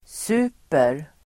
Uttal: [s'u:per]